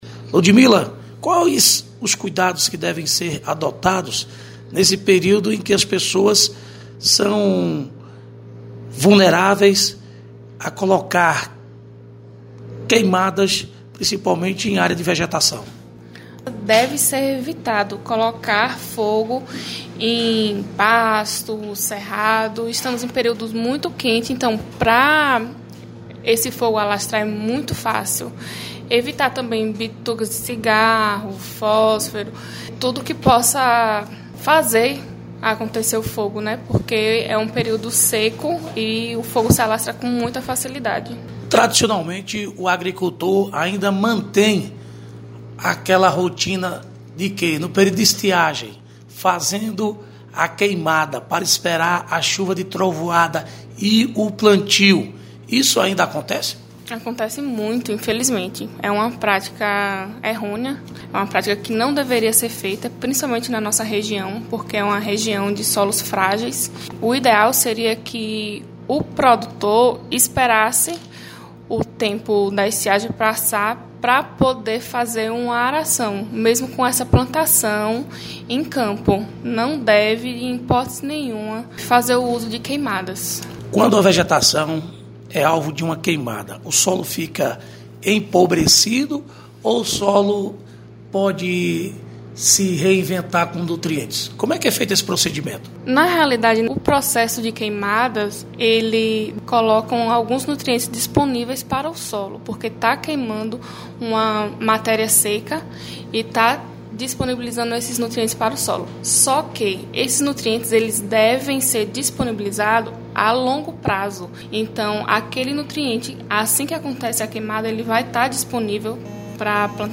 Engenheira agrônoma